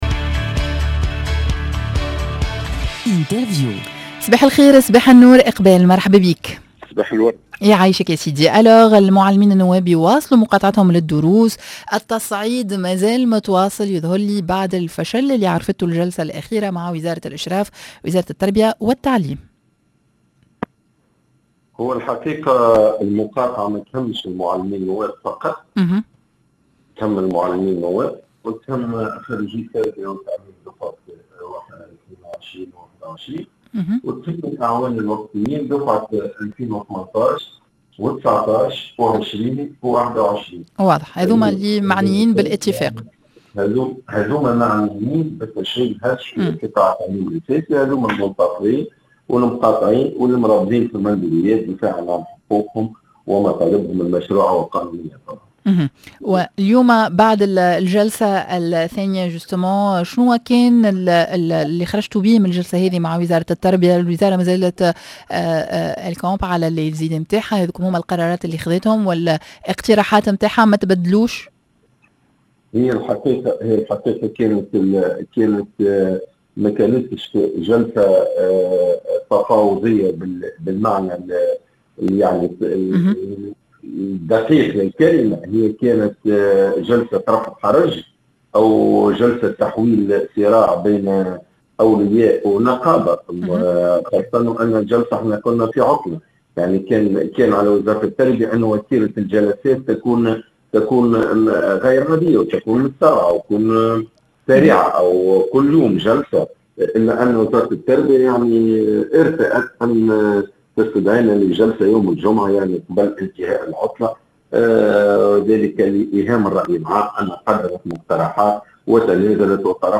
L'interview